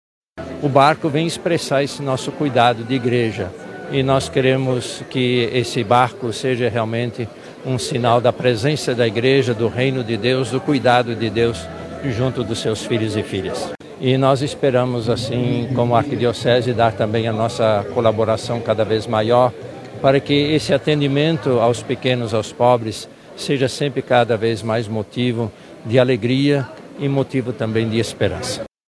A inauguração da embarcação ocorreu neste sábado, 07, no Mirante Lúcia Almeida, e contou com uma missa celebrada pelo Arcebispo Metropolitado da Arquidiocese de Manaus, Cardeal Leonardo Ulrich Steiner. Na ocasião, relatou que a iniciativa é retrato do cuidado de Deus com os seus filhos.